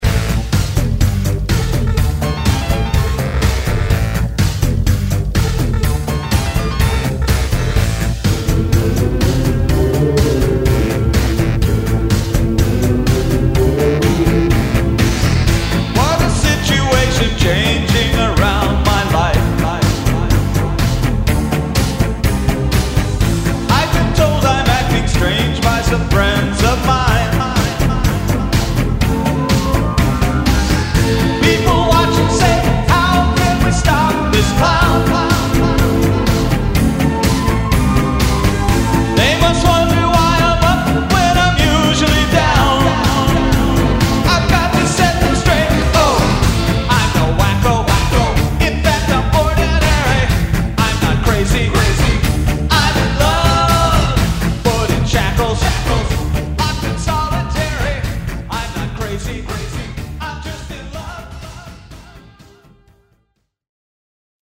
make up this joyful pop-rock songlist.